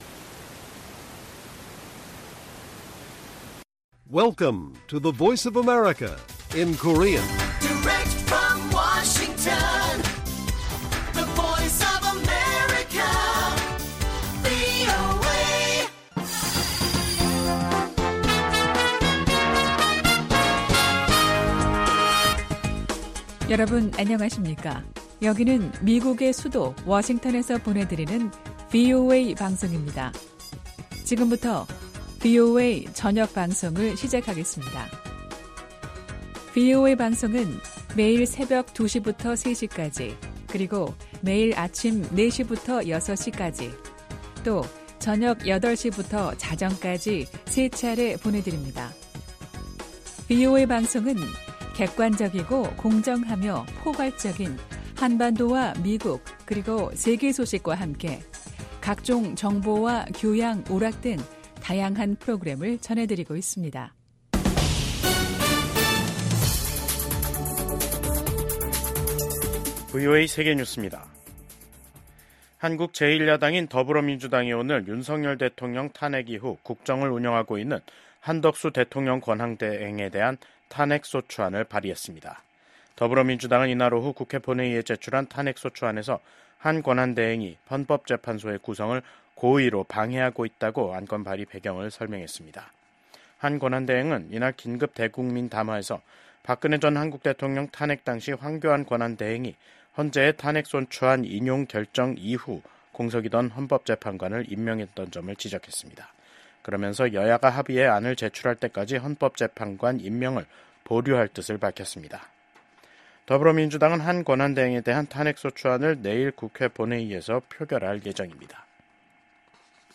VOA 한국어 간판 뉴스 프로그램 '뉴스 투데이', 2024년 12월 26일 1부 방송입니다. 비상계엄 사태와 대통령 직무정지로 한국 정정 불안이 지속되는 가운데 한국과 중국 두 나라는 외교장관 간 전화통화를 하는 등 소통을 재개했습니다. 미국 의회 중국위원회가 지난 1년 간의 조사 및 활동을 기술한 연례 보고서를 통해 중국 내 탈북민 문제와 관련된 심각한 인권 침해에 우려를 표명했습니다.